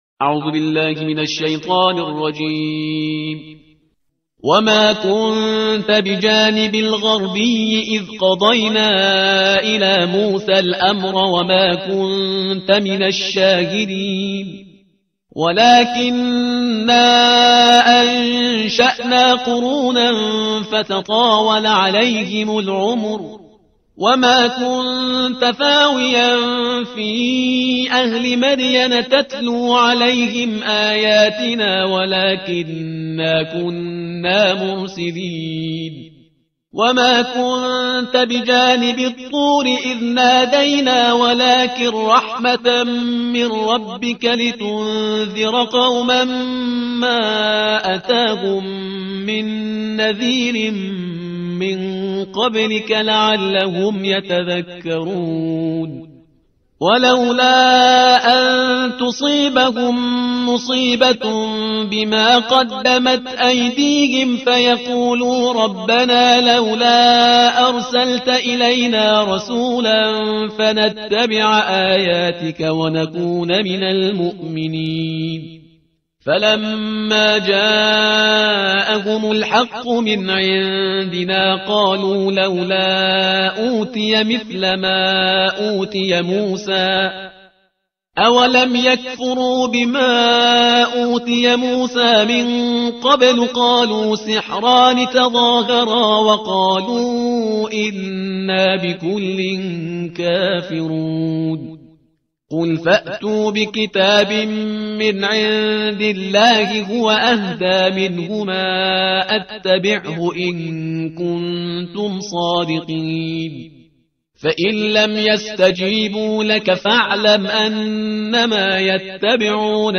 ترتیل صفحه 391 قرآن